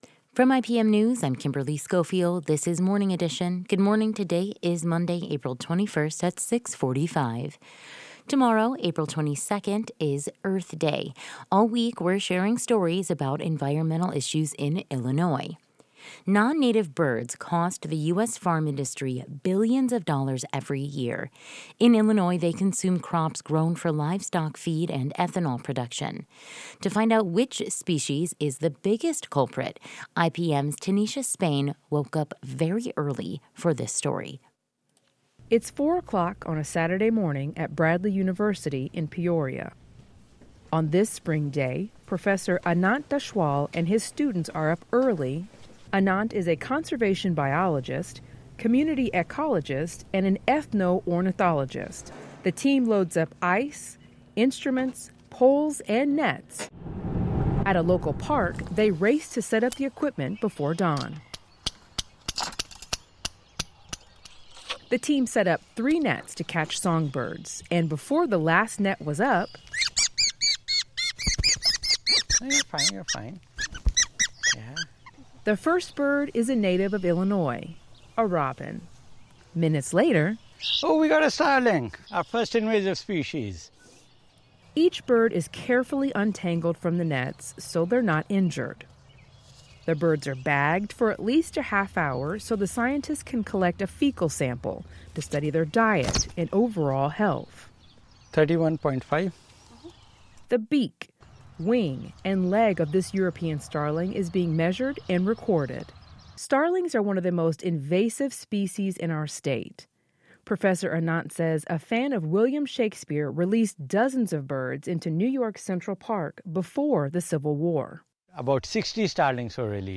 Before the last net is set up, a robin is heard squawking after it is caught.